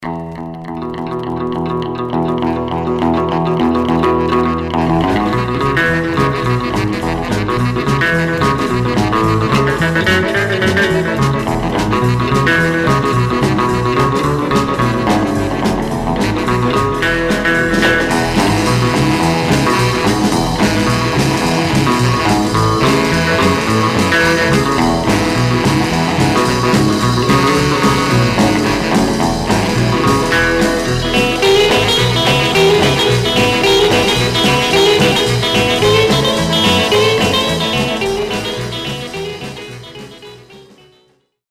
R & R Instrumental